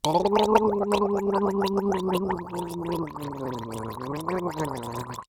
Human Male Gargling Sound
Human Male Gargling